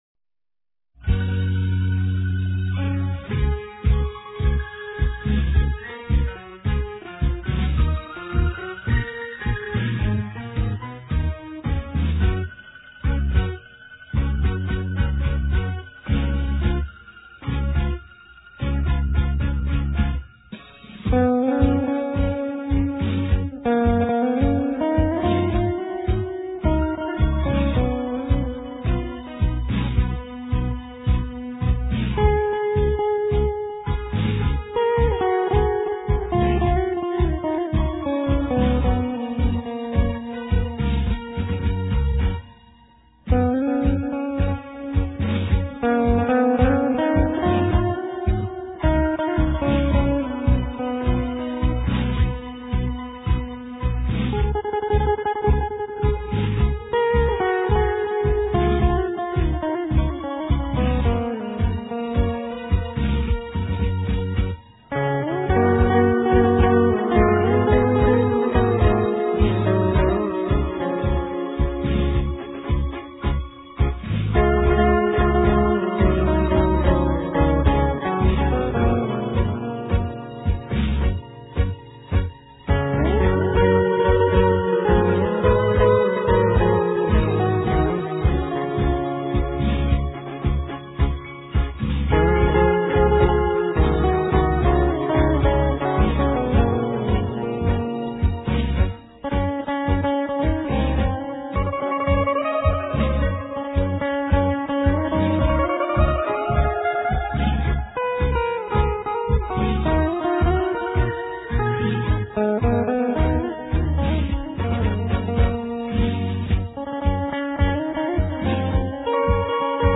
* Ca sĩ: Không lời
* Thể loại: Việt Nam